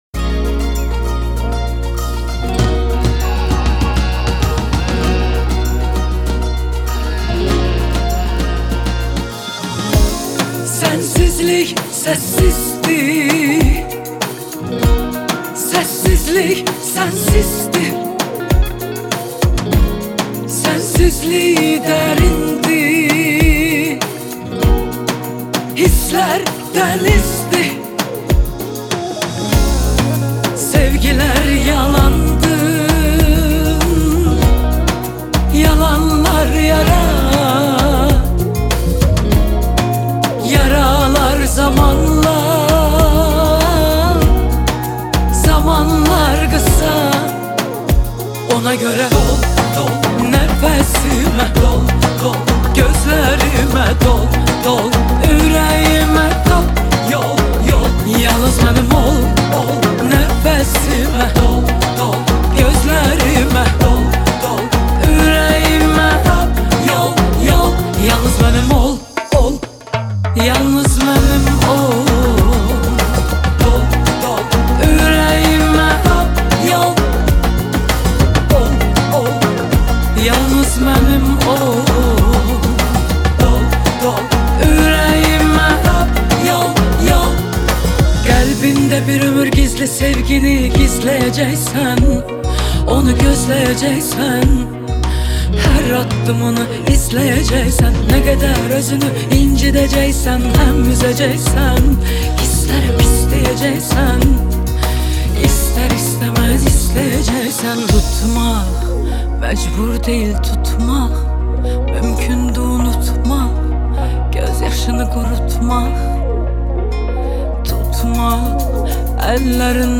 آهنگ آذربایجانی آهنگ غمگین آذربایجانی آهنگ هیت آذربایجانی
آهنگ ترکی